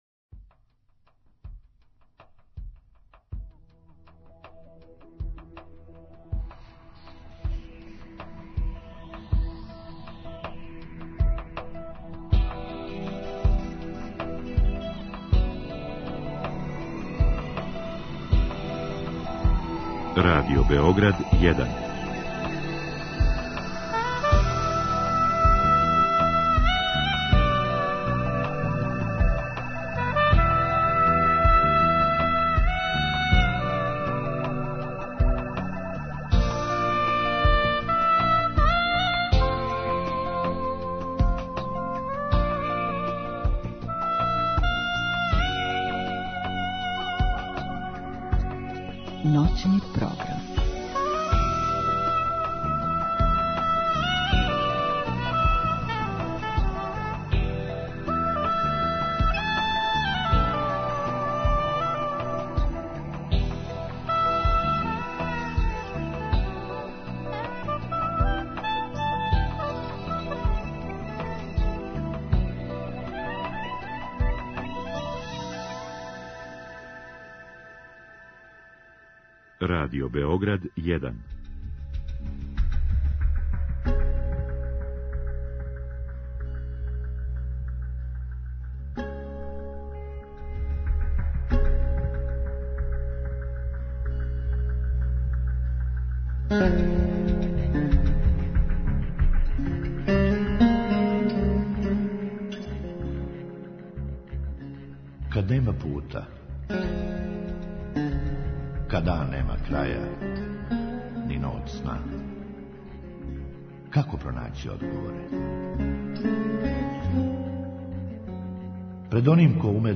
У другом сату слушаоци у директном програму могу поставити питање гошћи у вези са темом.